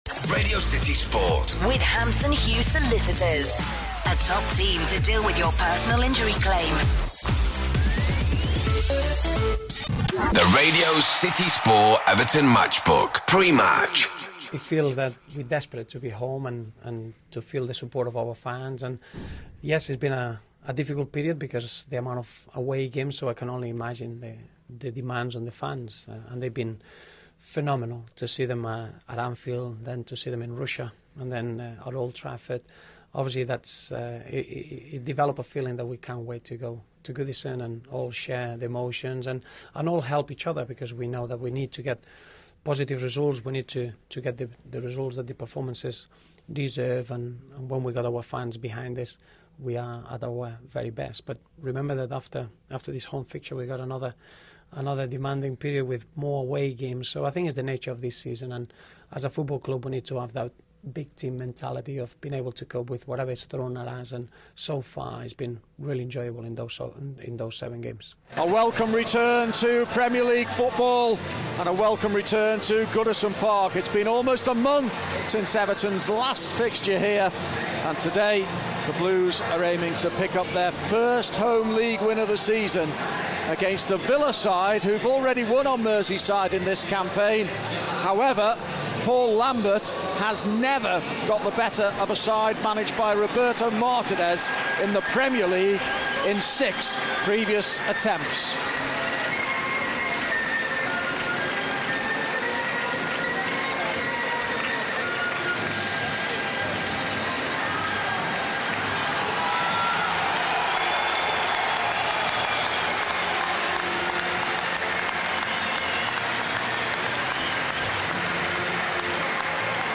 Hear how Everton beat Aston Villa at Goodison Park with your commentators